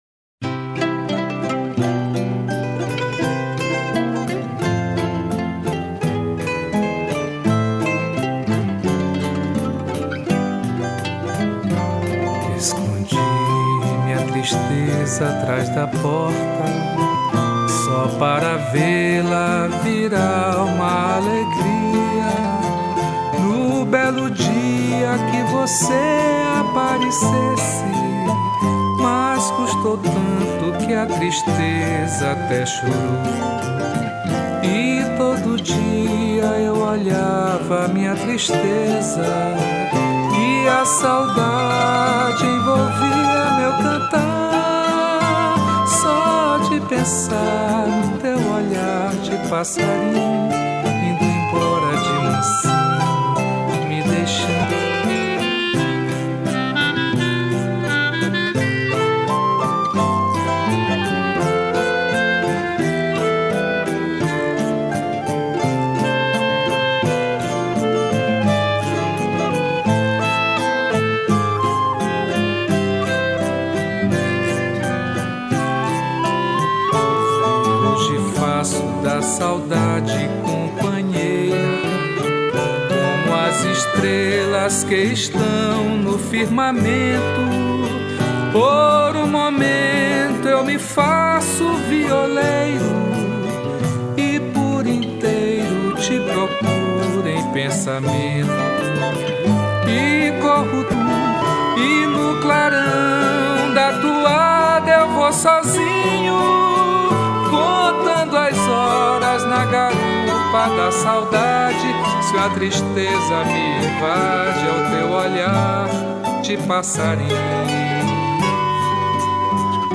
292   02:23:00   Faixa:     Bossa nova
Voz
Violao Acústico 6
Cavaquinho
Bandolim
Clarinete
Percussão